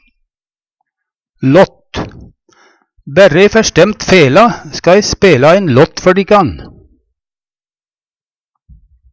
lått - Numedalsmål (en-US)